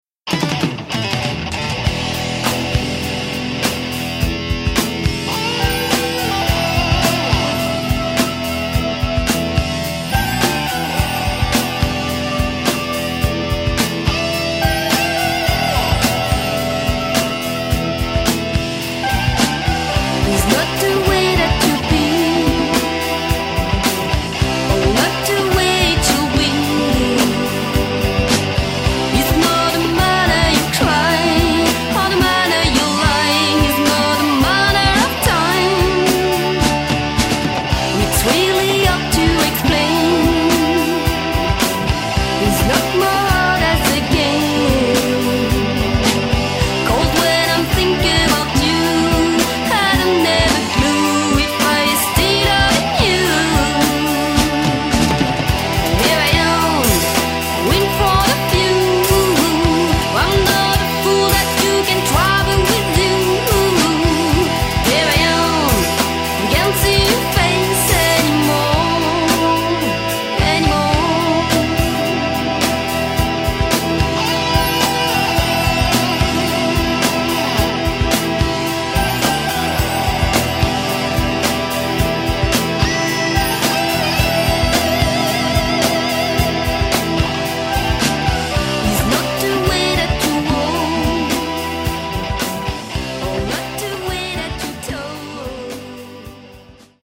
Enregistrement Studio Relief (FR)